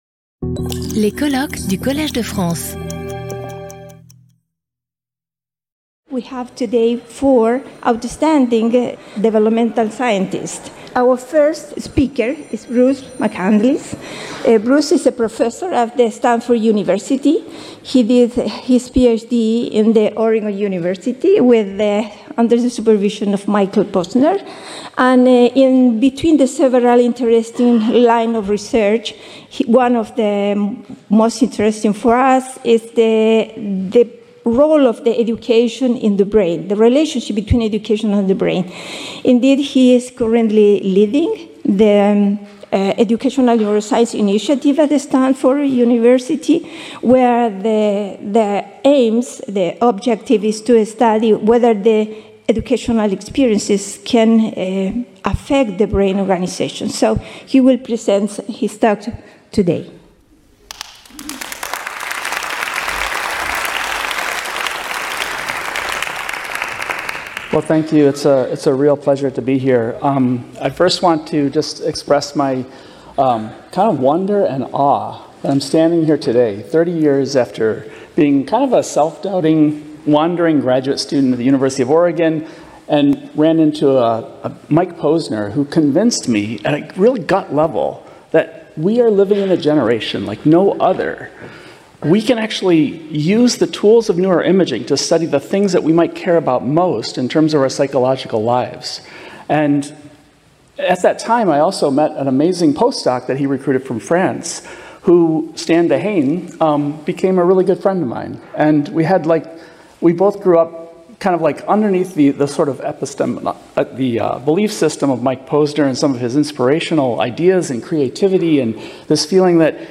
Symposium